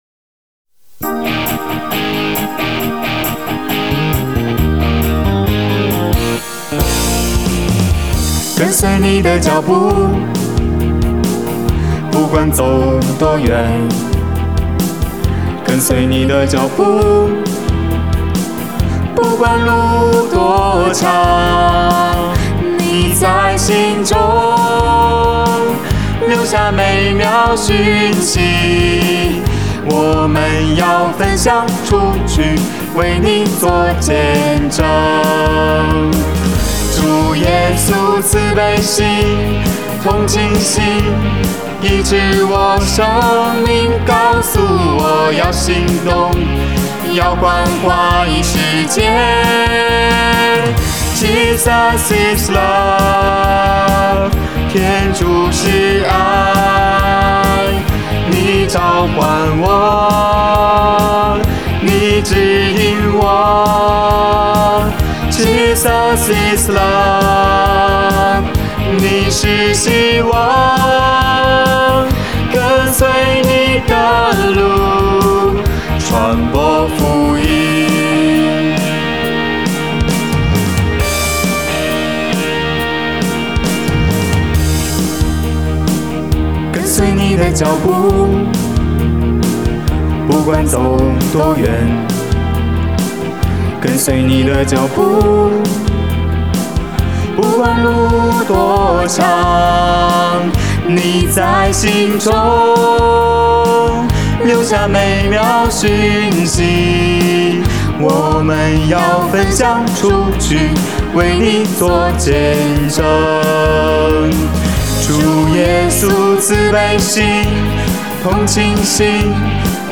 ｣（宗四20）；音乐的部份，选择「Pop Rock」流行摇滚的曲风，完成旋律和编曲。
这首看似简短的创作曲，却隐藏着背后深厚的意义，歌词简单明了，旋律节奏悠扬轻快，让人听了以后，充满了满满的正能量呢!